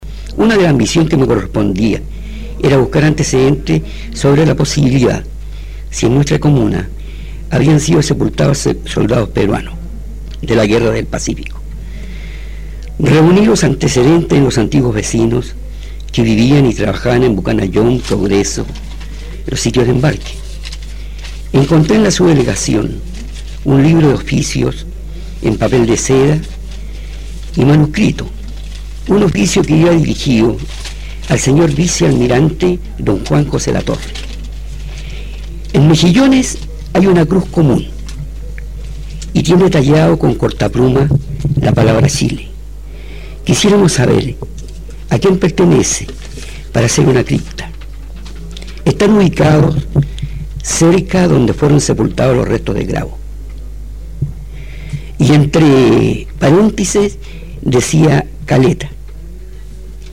ANTECEDENTES RESPECTO A LA QUE PODRÍA SER SU TUMBA: Al igual que con el primer cementerio de los caídos del “Huáscar”, fue Radio “Mejillones” la que dio luces de la que podría ser la tumba del Grumete Johnson.